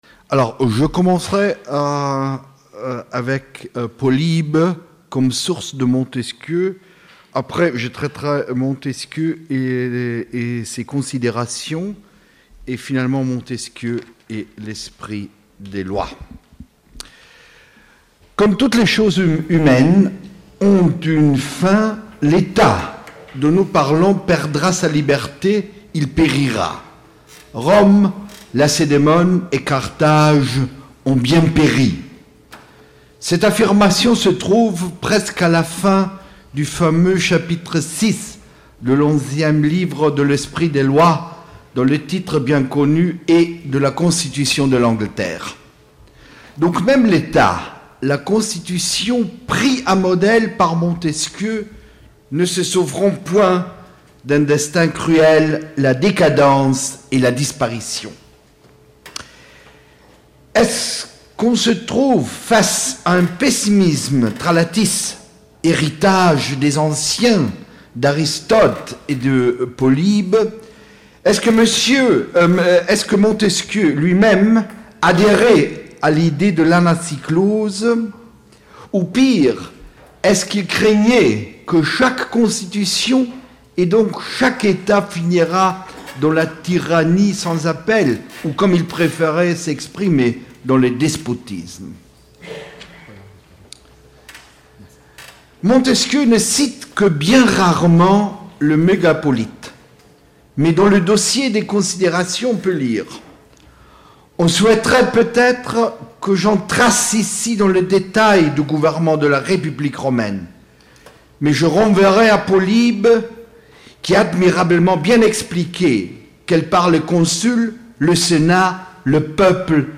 Du 1er au 4 juin dernier se sont déroulées à la Faculté de Droit de Tours, les Journées internationales de la Société d'Histoire du Droit, association scientifique internationale plus que centenaire. Le thème qui avait été proposé par notre Faculté était la Responsabilité.
Près de 180 auditeurs furent présents pour entendre 63 communicants d'une dizaine de nationalités différentes (française, espagnole, italienne, hollandaise, belge, polonaise, allemande, suisse, autrichienne, portugaise, hongroise et bulgare).